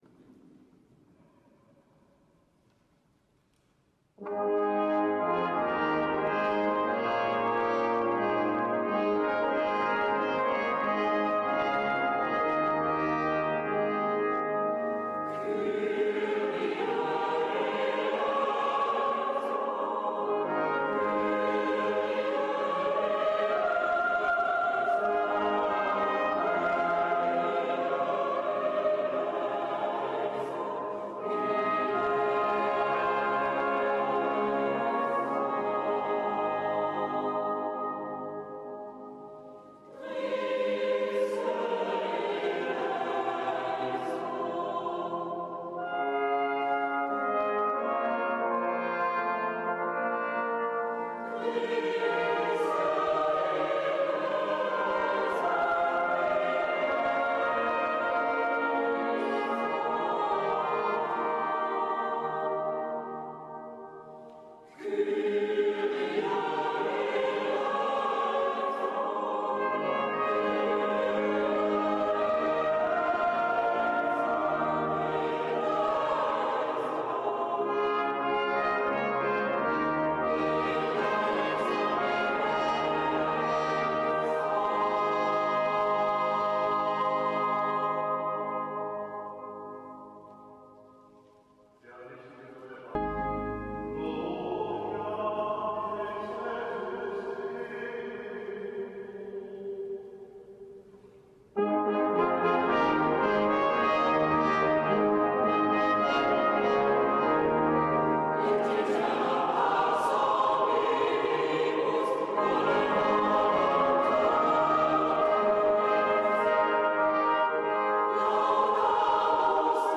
Gottesdienste
Gottesdienst im Trierer Dom